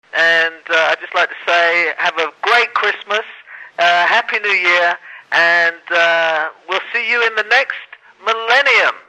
I managed to place a quick call to all four Bugaloos, and they all had warm greetings for all us fans on the web.